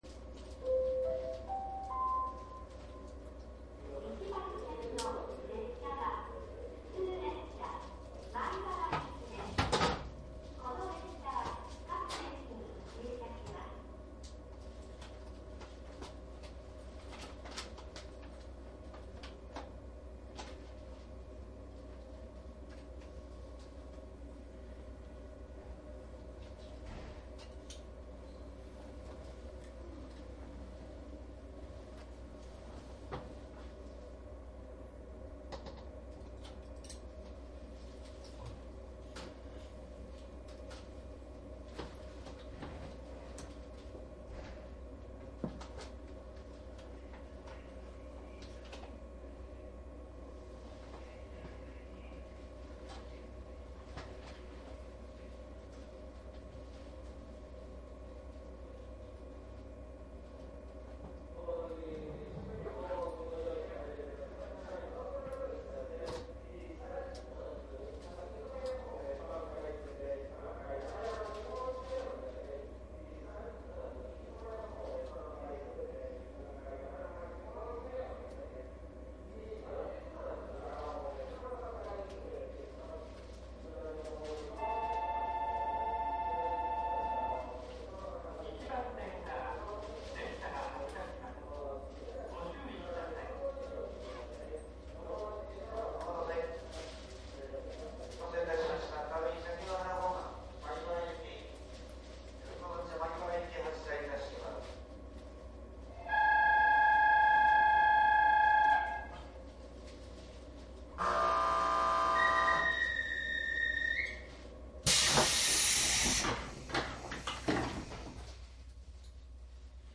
動力車での収録でモーター音や車内放送・ドア開閉音を楽しめるほか、乗車気分をご家庭のCDプレーヤーで気軽に楽しめます。音源はDAT使用のデジタル音源で、音質にこだわりました。
冬季限定で収録しておりますので空調音は一切ありません。クリアーな音を楽しめます。 鉄道走行音CD◆JR東海道線 快速 名古屋→大垣・大垣→米原（117系） 大垣までの快速は4両編成で残念ながら乗客が多かったです。